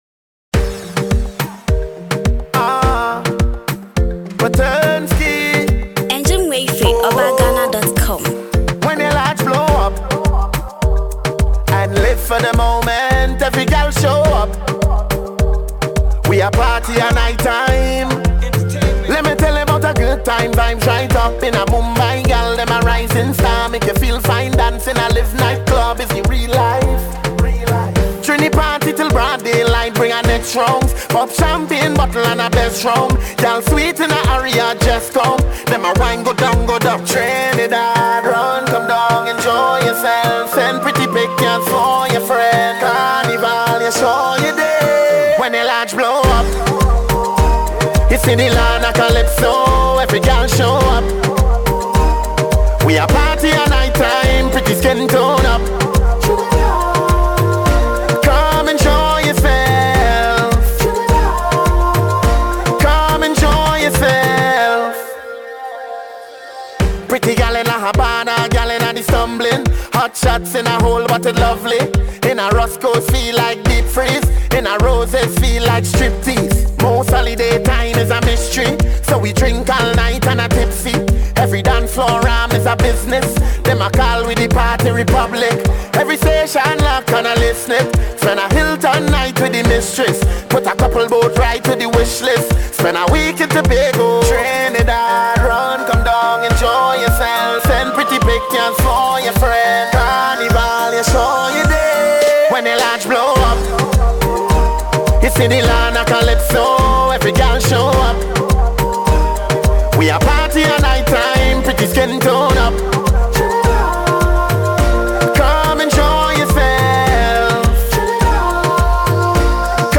Reggae/Dancehall
This is an amazing dancehall vibe!!